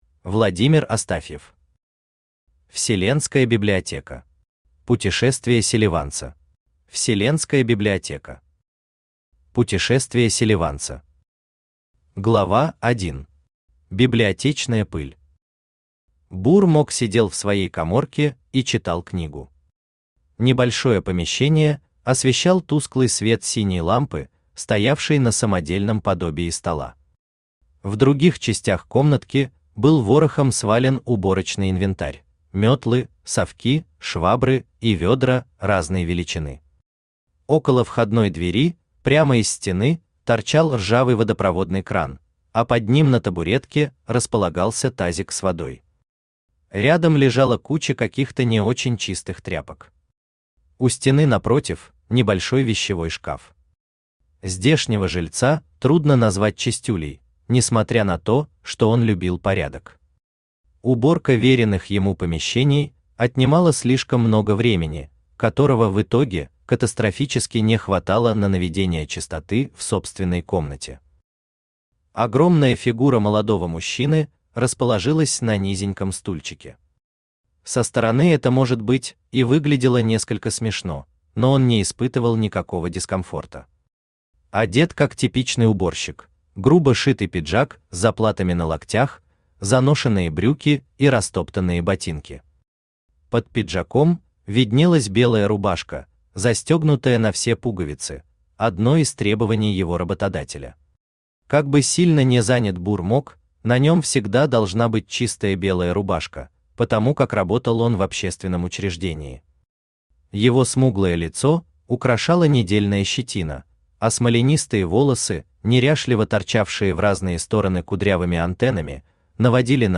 Аудиокнига Вселенская библиотека. Путешествие селеванца | Библиотека аудиокниг
Путешествие селеванца Автор Владимир Астафьев Читает аудиокнигу Авточтец ЛитРес.